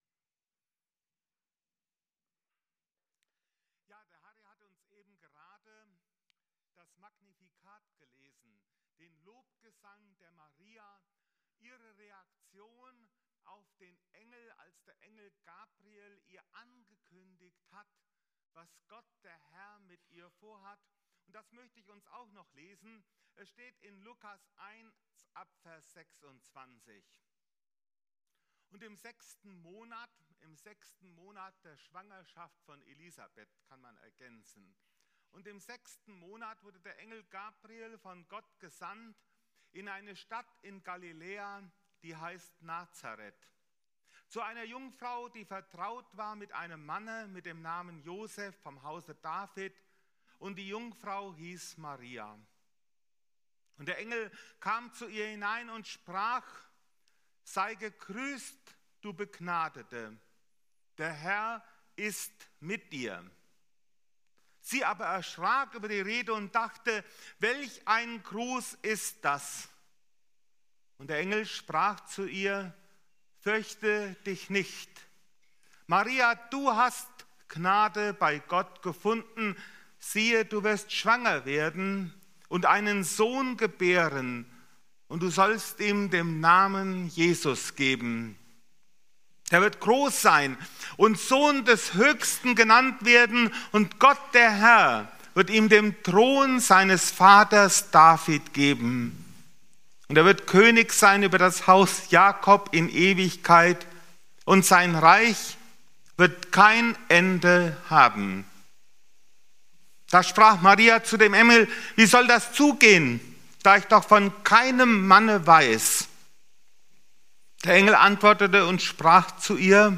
Predigten - FeG Steinbach Podcast